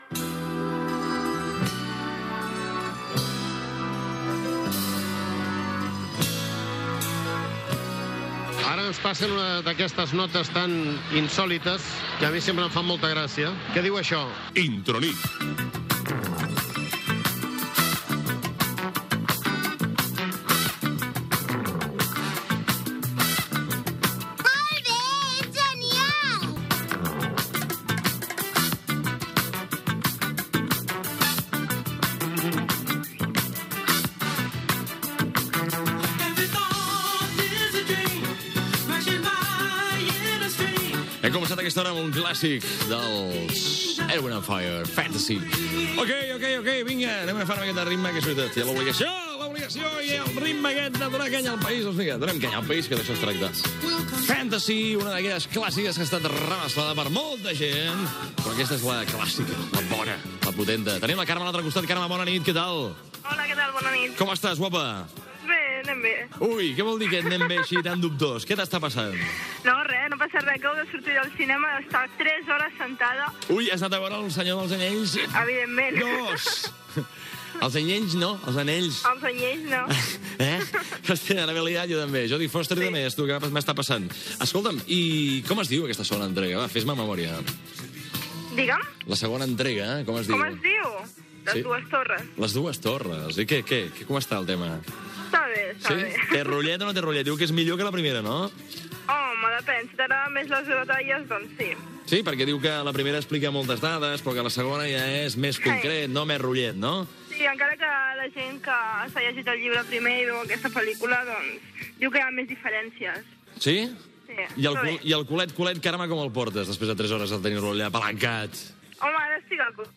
Careta del programa, tema muscial, trucada d'una oïdora i tema musical
Musical